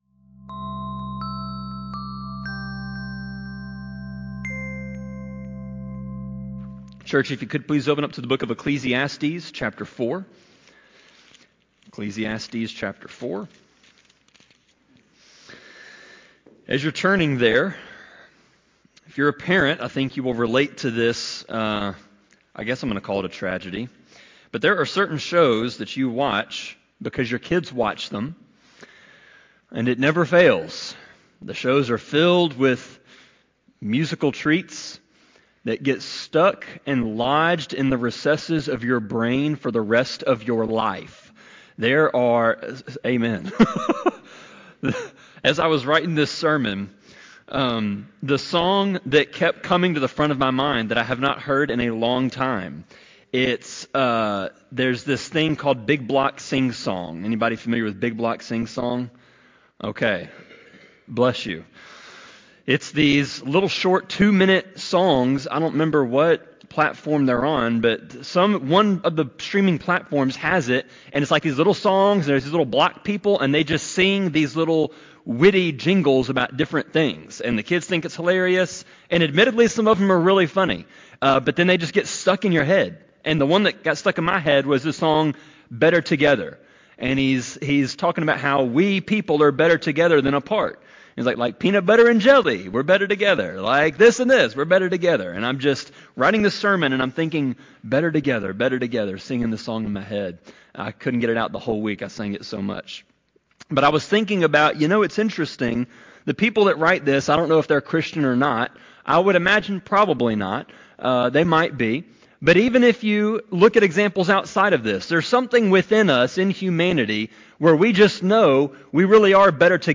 Sermon-25.2.16-CD.mp3